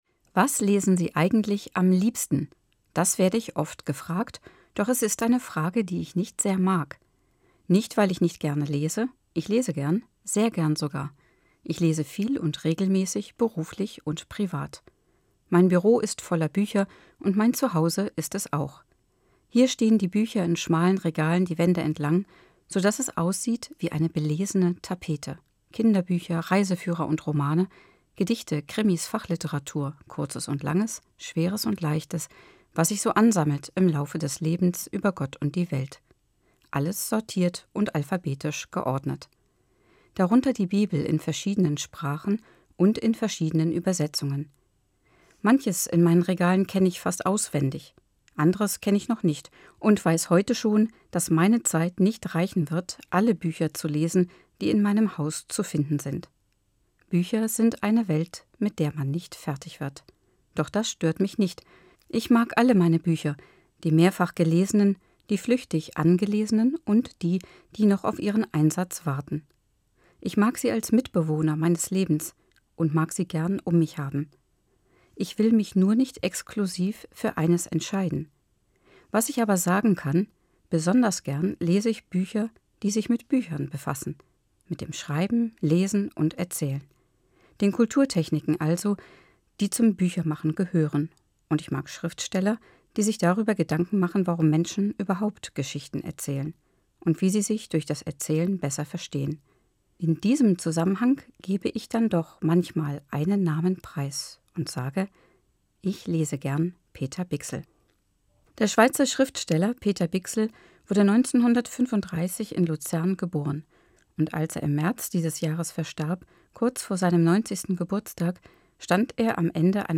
Musikkonzeption